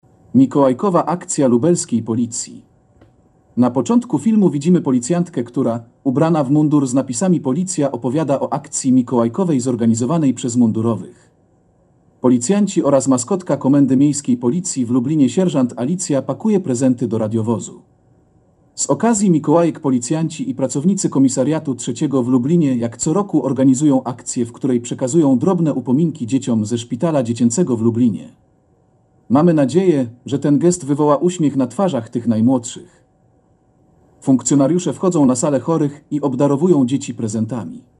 Nagranie audio Audiodeskrypcja Filmu Mikolajowa Akcja Lubelskiej Policji